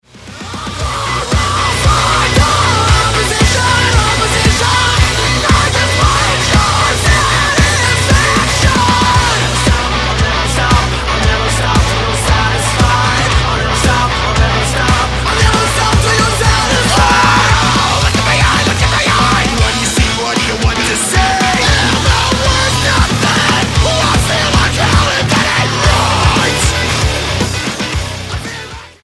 Metal band with handmade instruments
post hardcore band from Idaho
Style: Hard Music